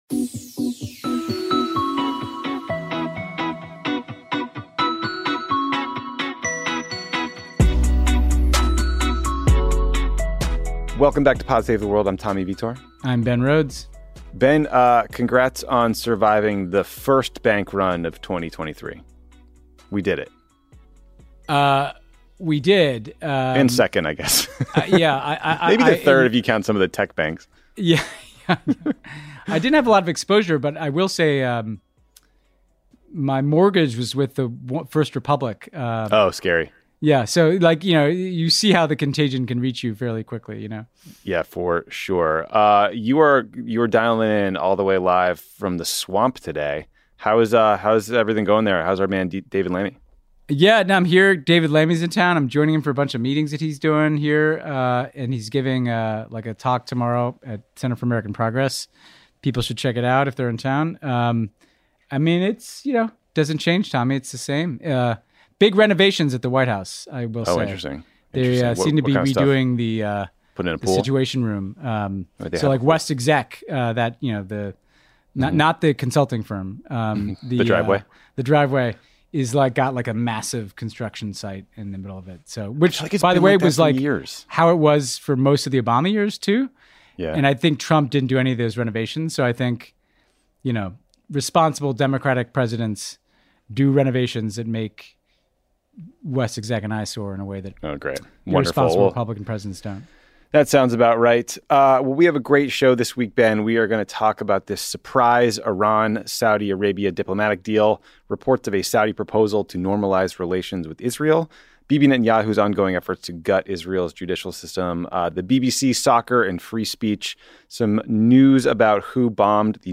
Tommy and Ben talk about China brokering a deal between Saudi Arabia and Iran, Saudi Arabia’s wish list to “normalize” relations with Israel, protests of Netanyahu’s changes to Israel’s judicial system, the BBC, soccer and free speech, new reports about who sabotaged the Nord Steam gas pipeline, the president of Mexico’s bizarre press conference, momentum behind banning TikTok, and Canada’s stance on flipping the bird.